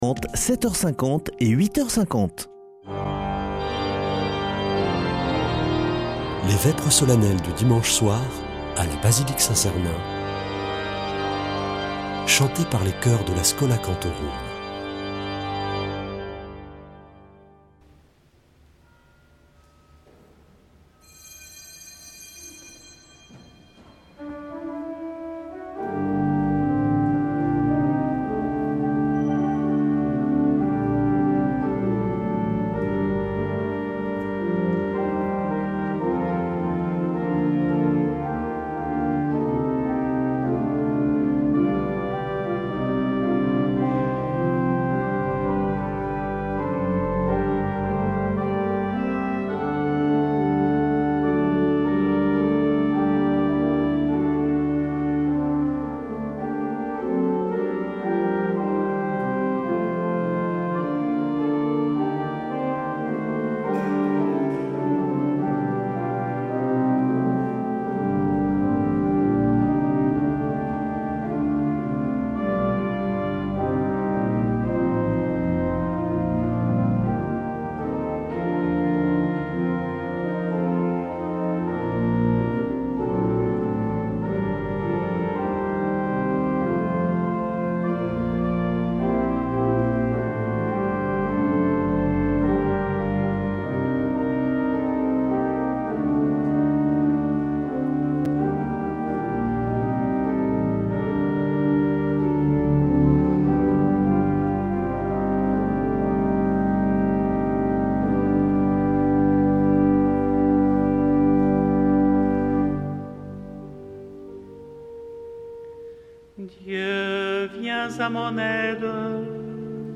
Vêpres de Saint Sernin du 22 sept.